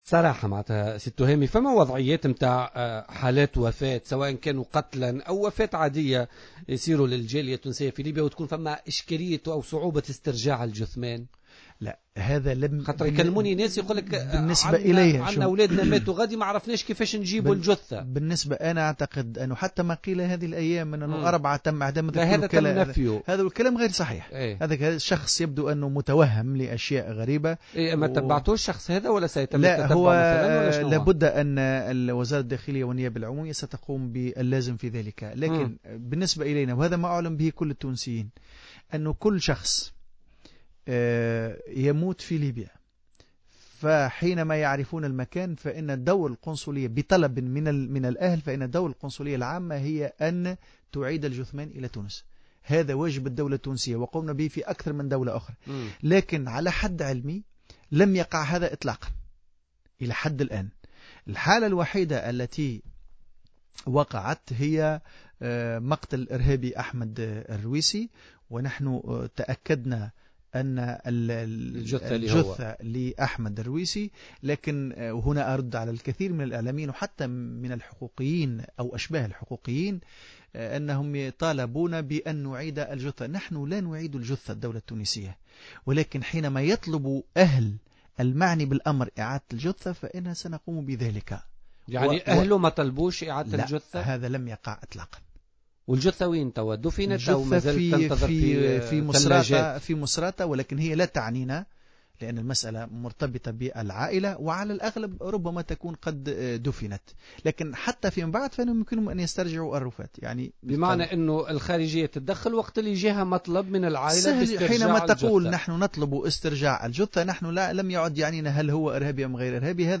وفسر العبدولي، ضيف بوليتيكا اليوم الاثنين 20 أفريل، أن المسألة أصبحت لا تعني الخارجية التونسية لغياب أي طلب لتسلم الجثة التي يرجح أنها دفنت في مصراطة، لكن بإمكانها التحرك لاسترجاعها لاحقا، مبينا أن الديبلوماسية تتعامل مع أي طلب بشكل إيجابي حتى وإن تعلق الأمر بجثة إرهابي، ومن واجبها التحرك لتسليمها لذويها.